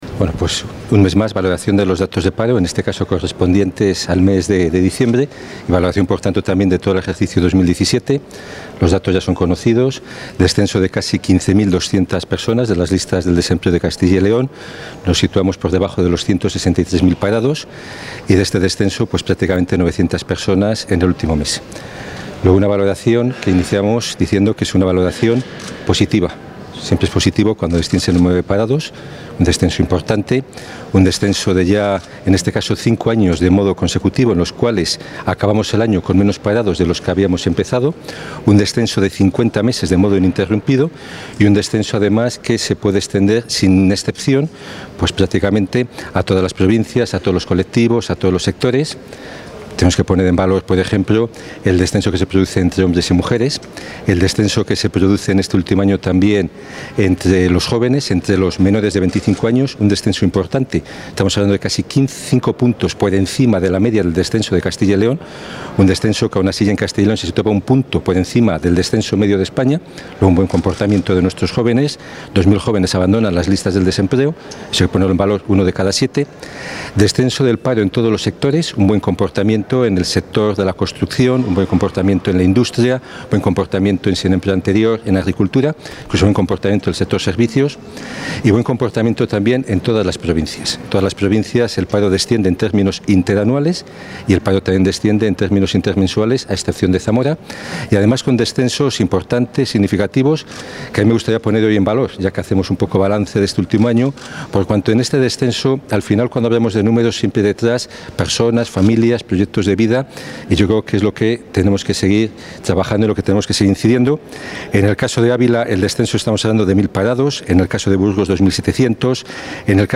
Audio viceconsejero de Empleo y Diálogo Social.
El viceconsejero de Empleo y Diálogo Social, Mariano Gredilla, ha valorado hoy los datos del paro del mes de diciembre y del año 2017.